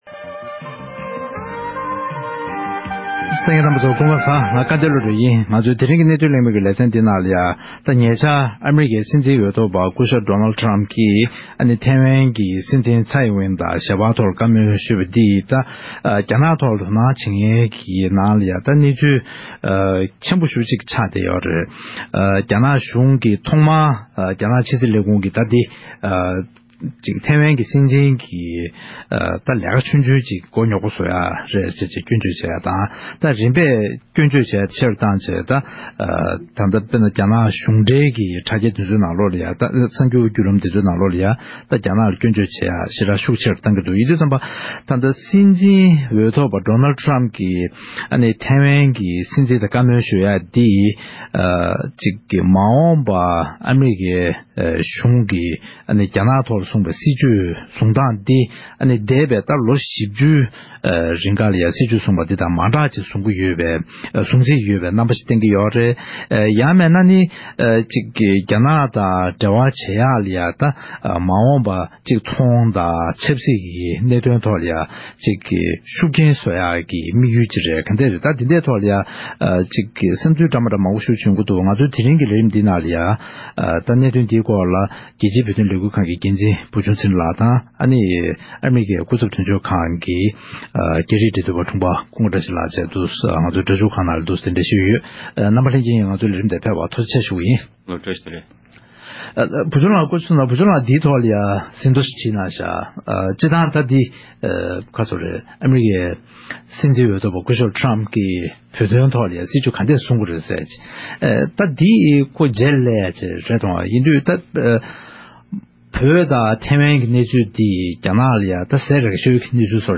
༄༅། །ཐེངས་འདིའི་གནད་དོན་གླེང་མོལ་གྱི་ལེ་ཚན་ནང་། ཨ་རིའི་སྲིད་འཛིན་འོས་ཐོབ་པ་སྐུ་ཞབས་ཌོ་ནལཌ་ཊ་རམཔ་མཆོག་གིས་ཐེ་ཝན་གྱི་སྲིད་འཛིན་ལྕམ་ཚཡི་དབྱིང་ཝན་དང་ཞལ་པར་ཐོག་བཀའ་མོལ་གནང་ཡོད་པ་ལྟར། ཨ་རིས་རྒྱ་ནག་ཐོག་འཛིན་པའི་སྲིད་བྱུས་ལ་འགྱུར་བ་འགྲོ་མིན་ཐོག་གླེང་མོལ་ཞུས་པ་ཞིག་གསན་རོགས་གནང་།